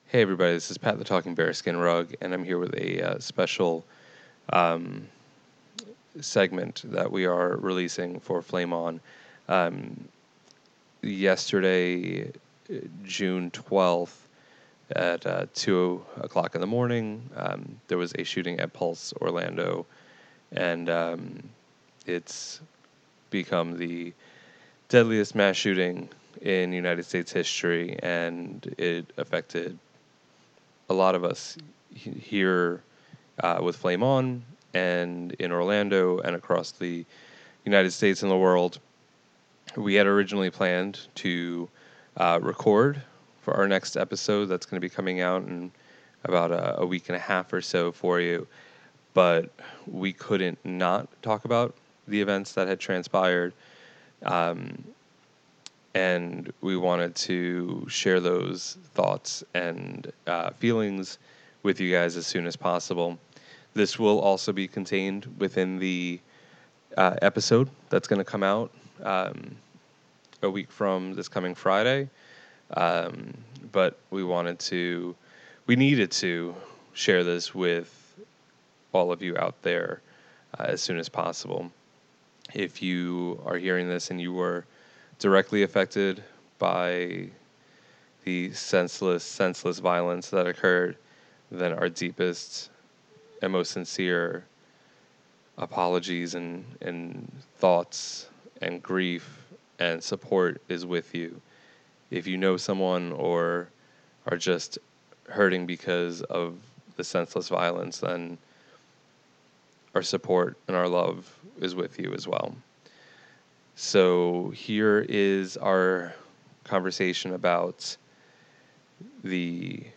There has been no editing done to this audio. This is the raw and emotional reaction and stories of our family.